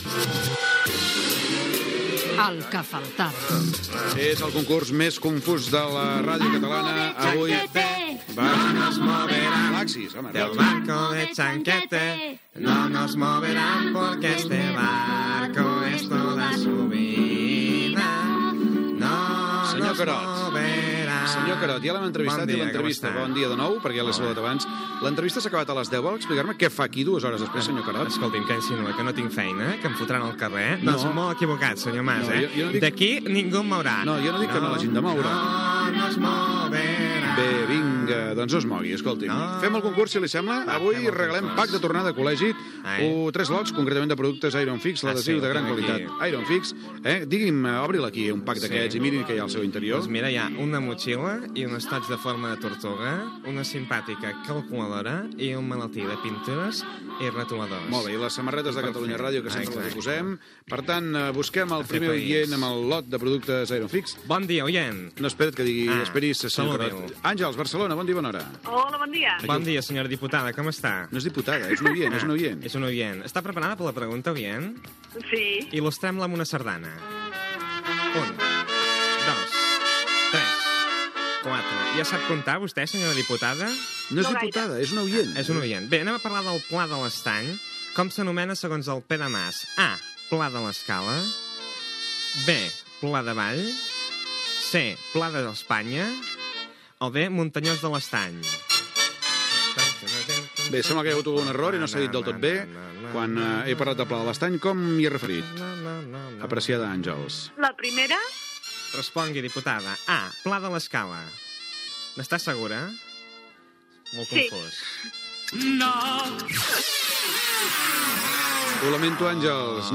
Espai "El que faltava". Concurs amb la participació dels oïdors i la imitació del polític Josep-Lluís Carod Rovira (que fa Ricard Ustrell)
Info-entreteniment